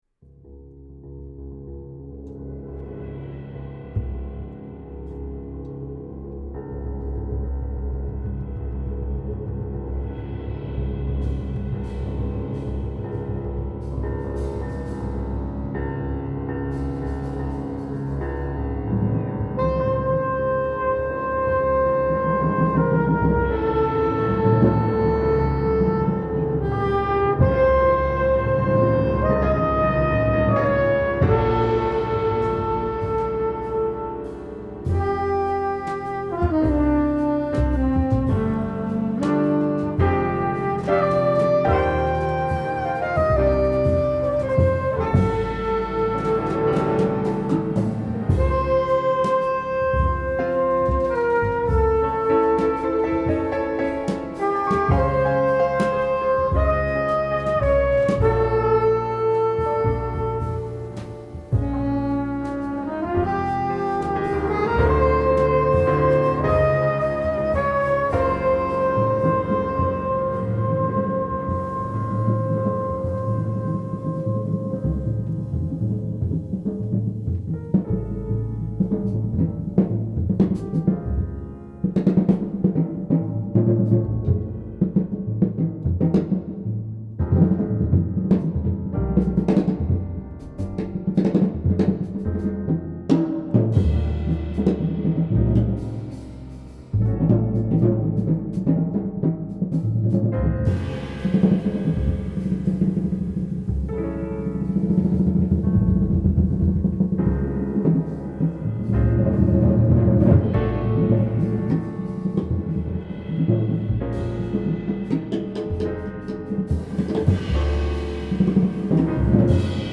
composition, programming, synths and live piano
soprano and tenor sax
flugelhorn and trumpets
drums
bass